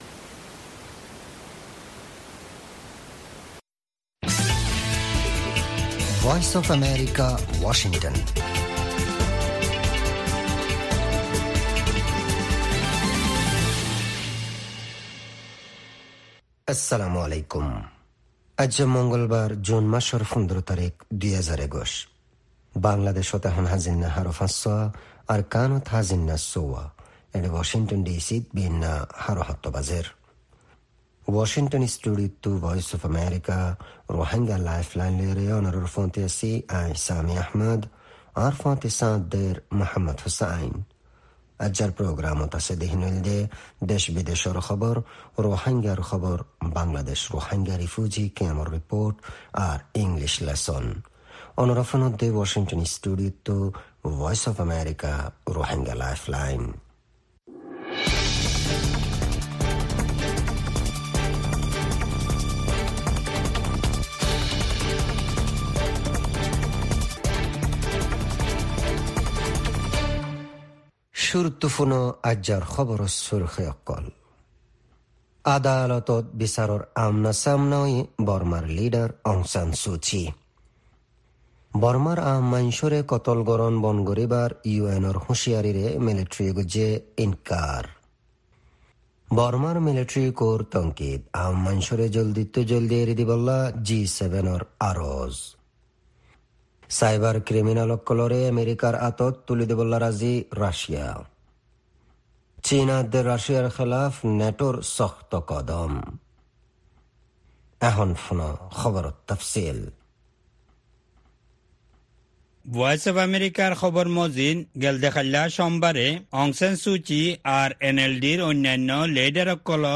Rohingya “Lifeline” radio